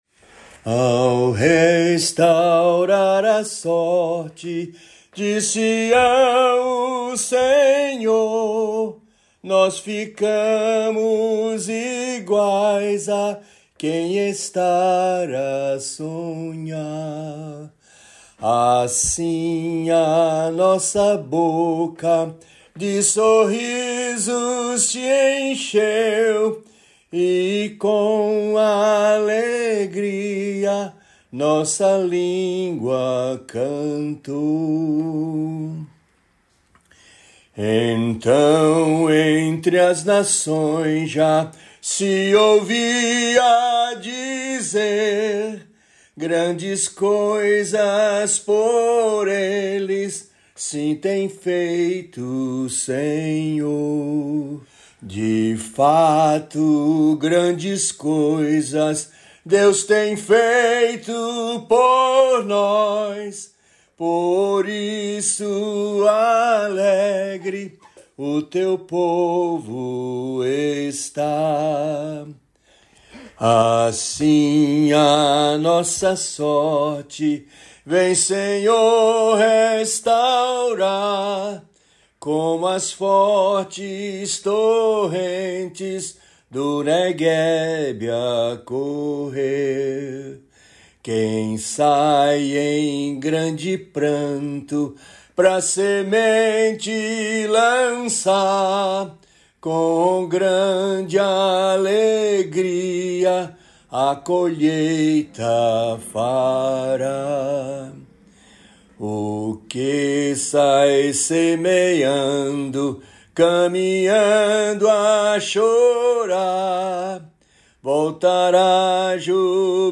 salmo_126B_cantado.mp3